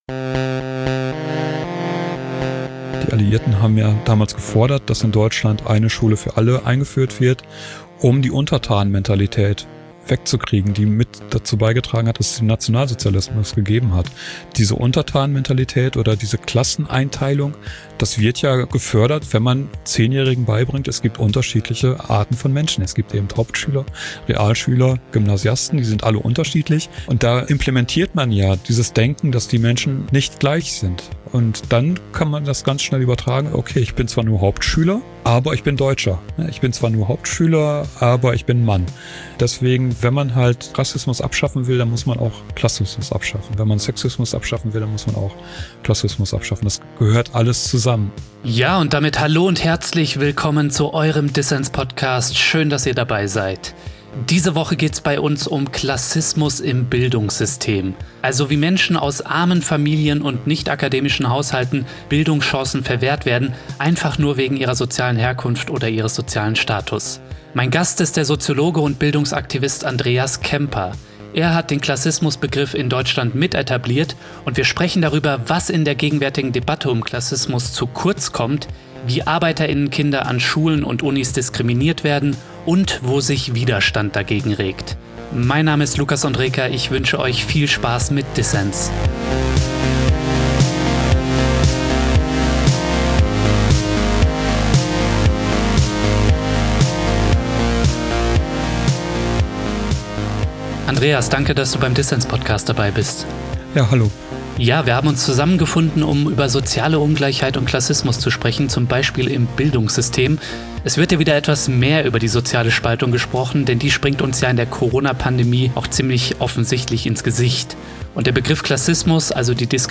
Ein Gespräch über die Diskriminierung von Schulkindern aus der Working Class und ein gerechtes Bildungssystem für alle.